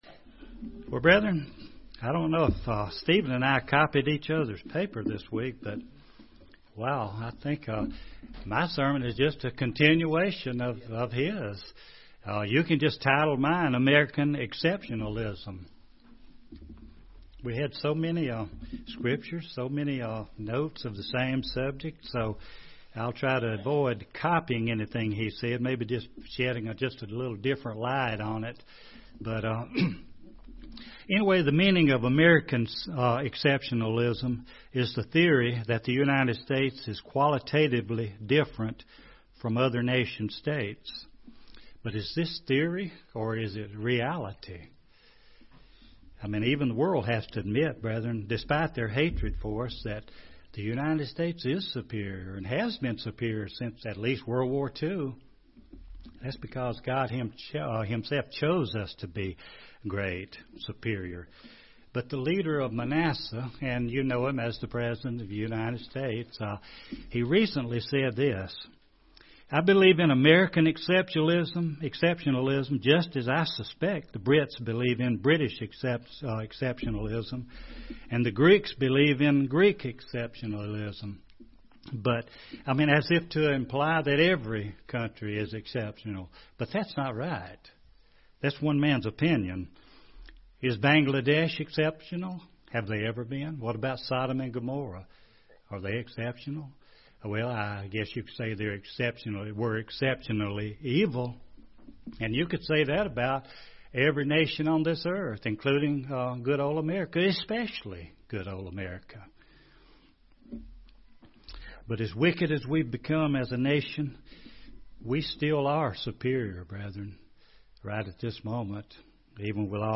Given in Gadsden, AL
UCG Sermon Studying the bible?